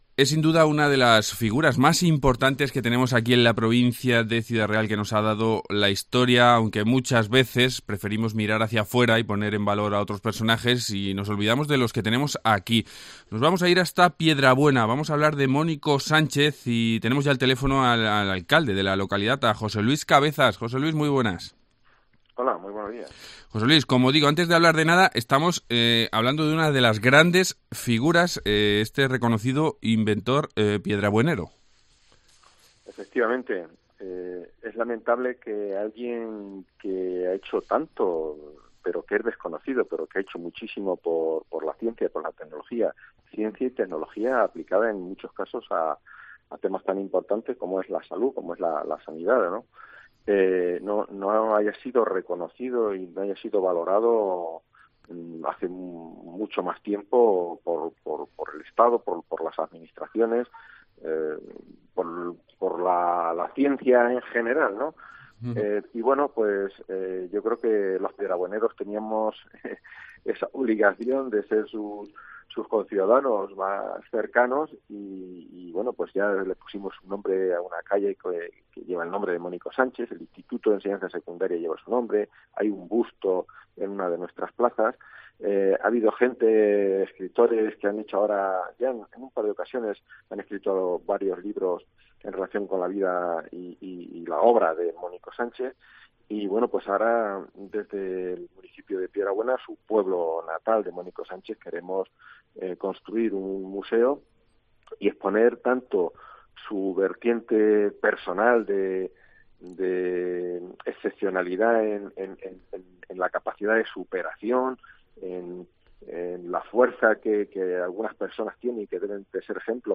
Entrevista José Luis Cabezas, alcalde de Piedrabuena